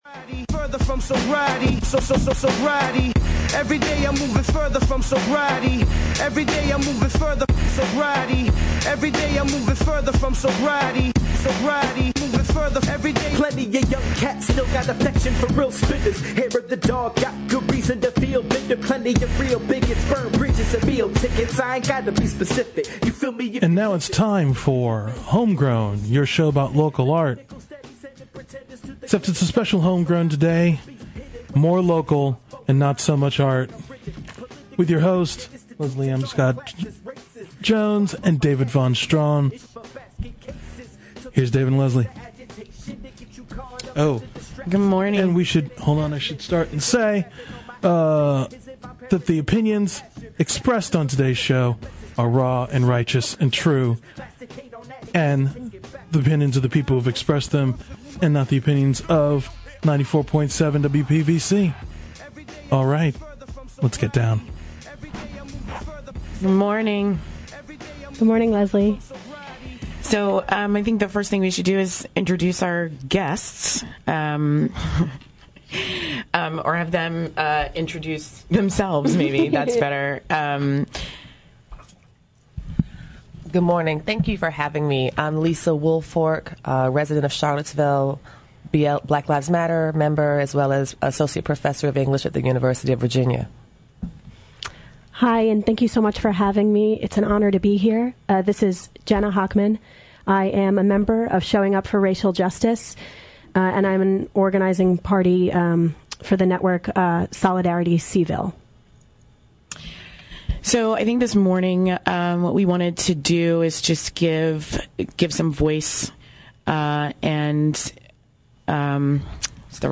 It’s not a very artistic talk, but as maybe the first media show afterwards, we had to give voice to what went down.